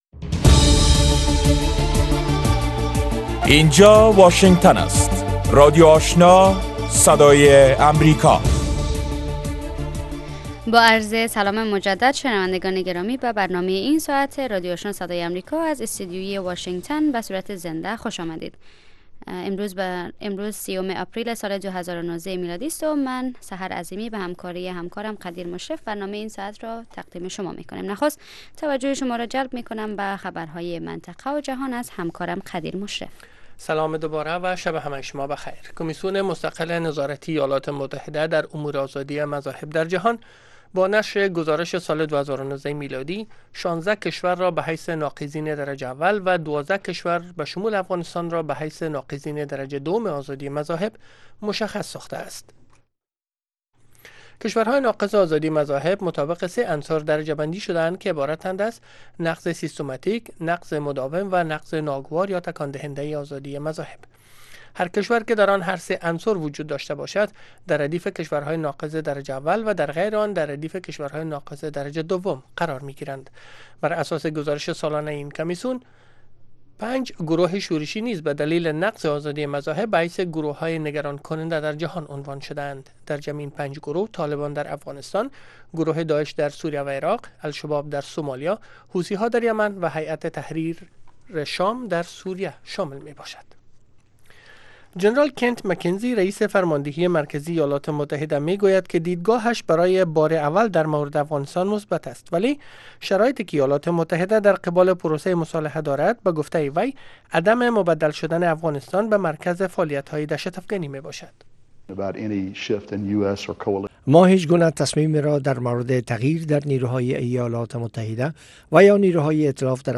دومین برنامه خبری شب